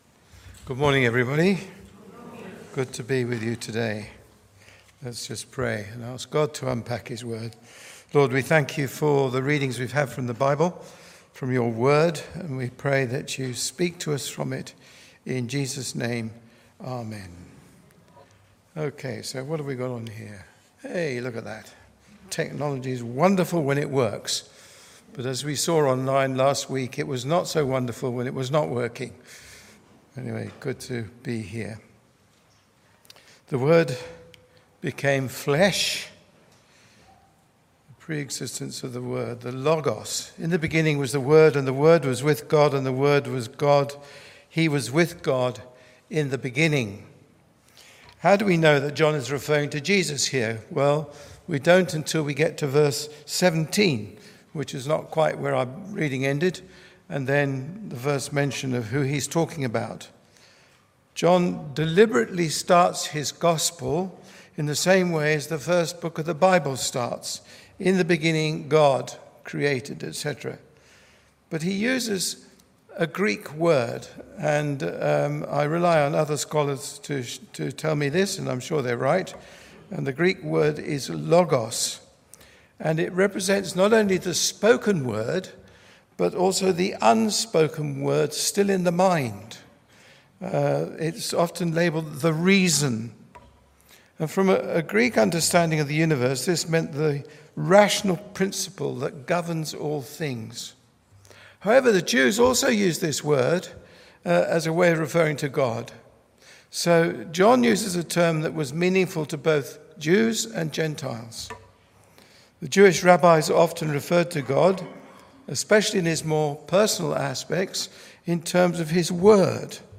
Listen to the sermon on John 1 in our The Incarnation series.